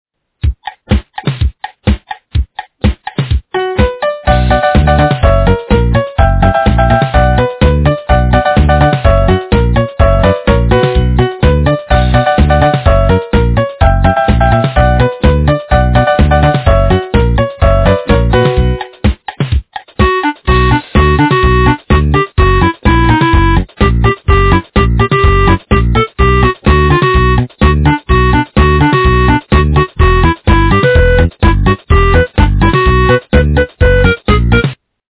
- украинская эстрада